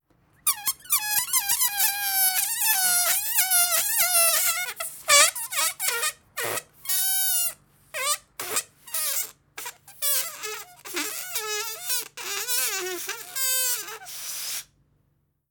Efecto cómico de desinflado de un globo (trompetilla)